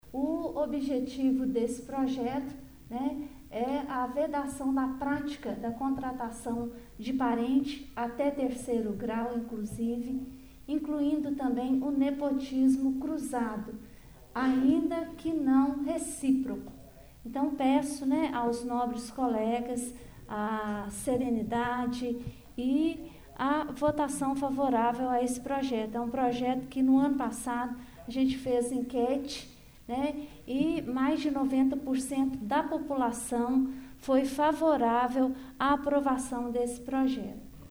O Portal GRNEWS acompanhou no plenário da Câmara de Vereadores de Pará de Minas mais uma reunião ordinária na noite desta Quarta-Feira de Cinzas, 02 de março.